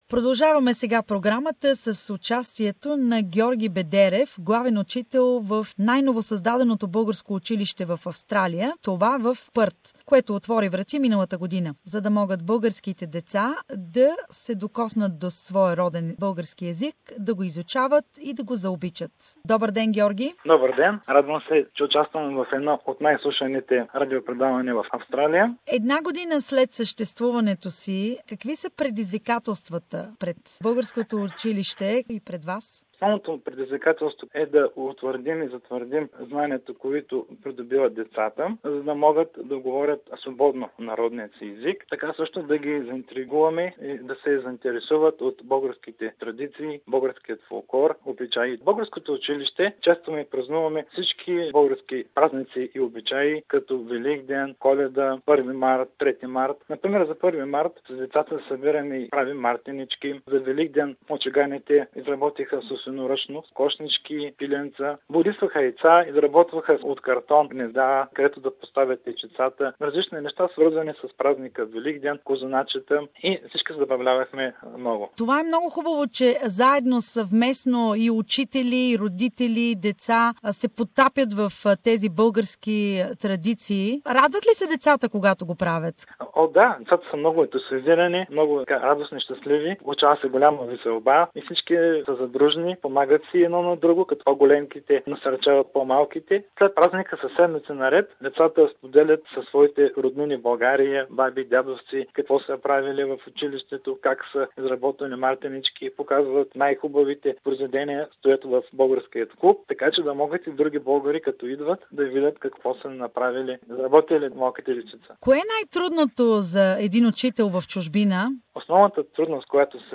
Предизвикателста и постижения в българското училище в Пърт, Западна Австралия - интервю